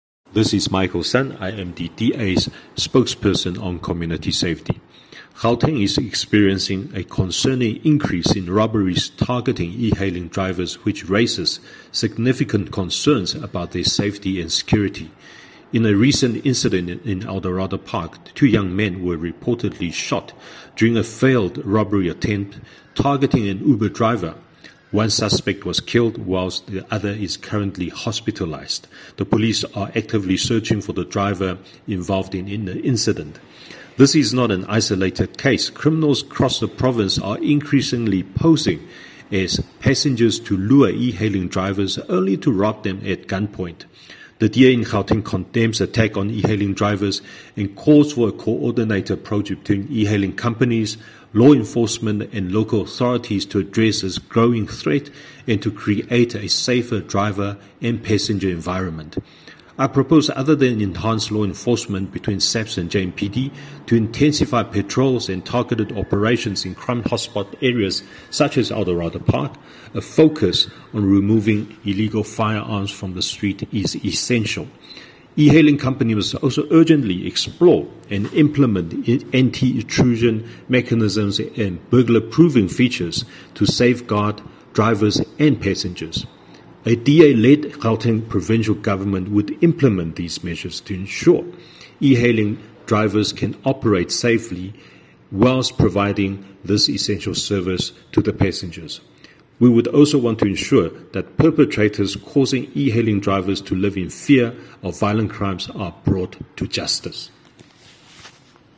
Issued by Michael Sun MPL – DA Gauteng Spokesperson for Community Safety
English soundbite by Michael Sun MPL.